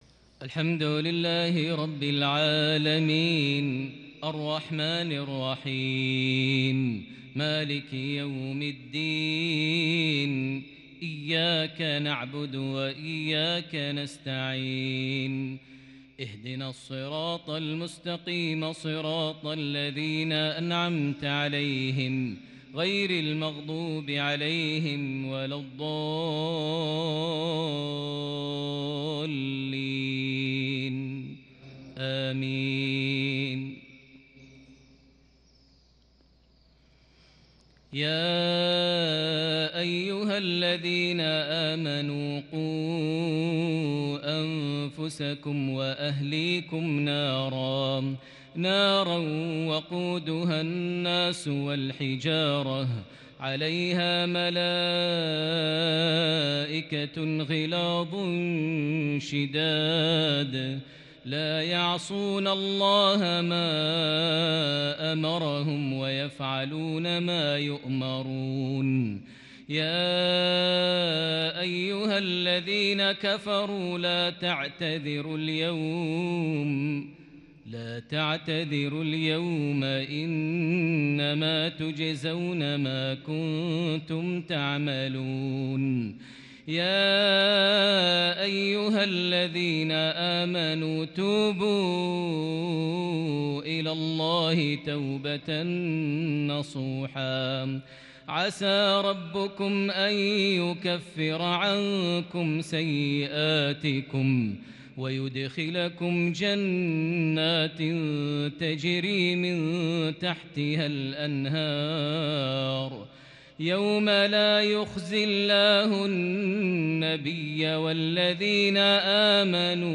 مغربية كردية فريدة لخواتيم سورة التحريم | 22 شعبان 1442هـ > 1442 هـ > الفروض - تلاوات ماهر المعيقلي